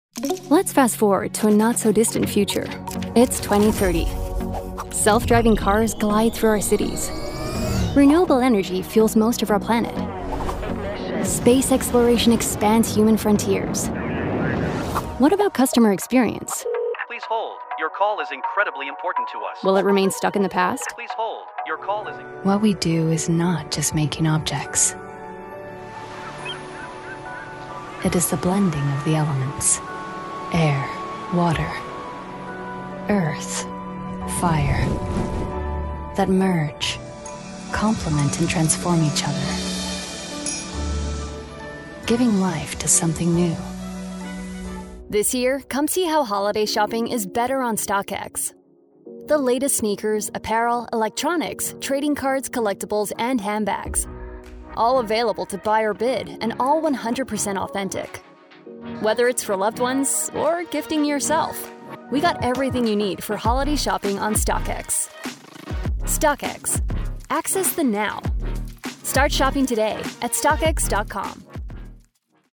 Female
Bright, Character, Cool, Engaging, Friendly, Natural, Soft, Warm, Witty, Versatile, Approachable, Conversational, Funny, Upbeat, Young
Microphone: Rode NT1-A
Audio equipment: Focusrite Scarlett 2i2, bespoke built vocal isolation booth